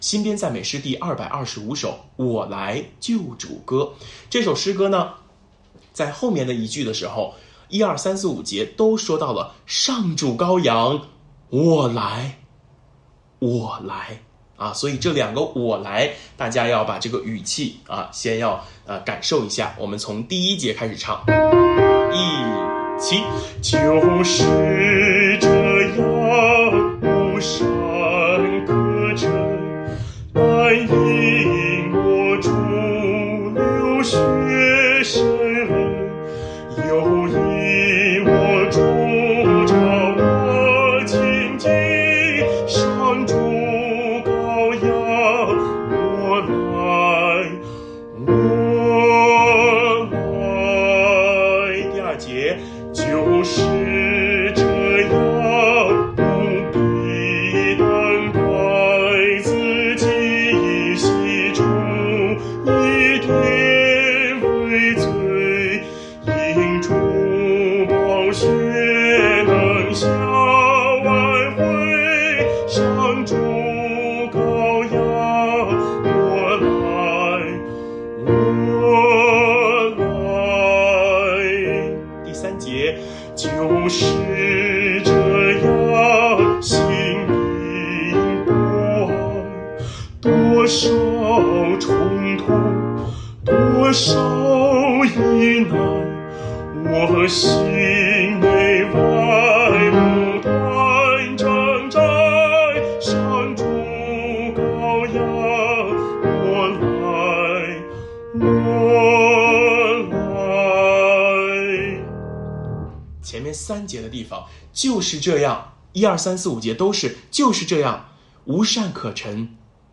【颂唱练习】
温馨提示：每次礼拜前十五分钟进行崇拜预习和诗歌颂唱学习。